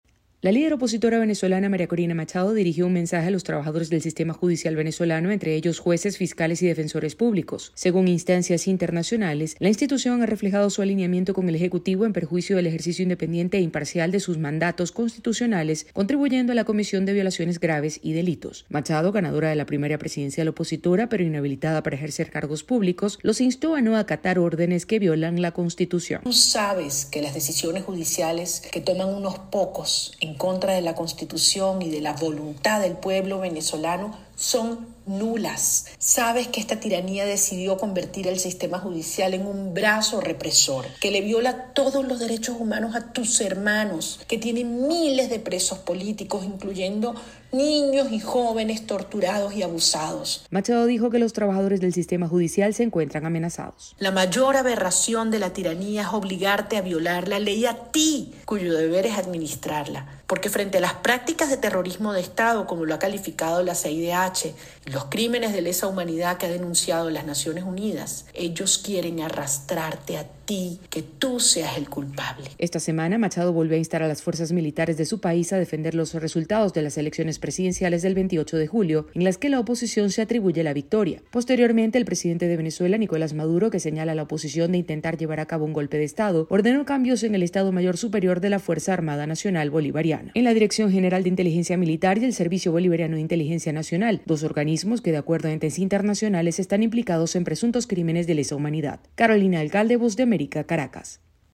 La líder política, María Corina Machado, continúa enviando mensajes a diversos sectores de la sociedad venezolana. El reporte